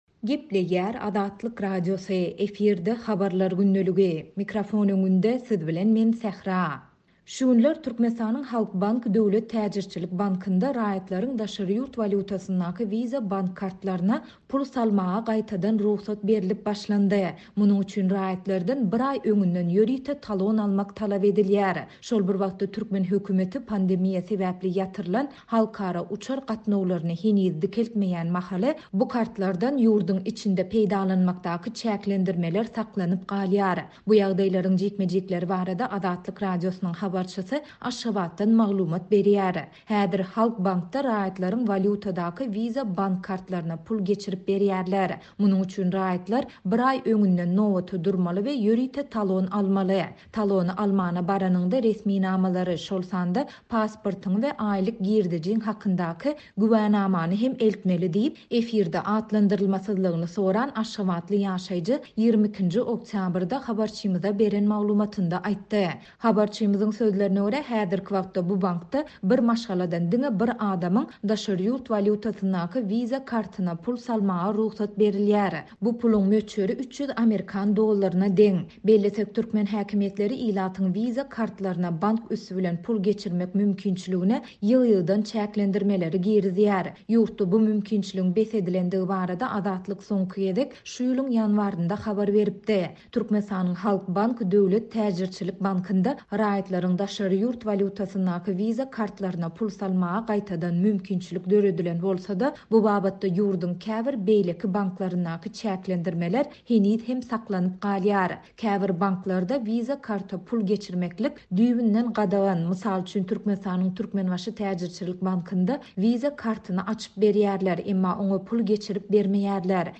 Bu ýagdaýlaryň jikme-jikleri barada Azatlyk Radiosynyň habarçysy Aşgabatdan maglumat berýär.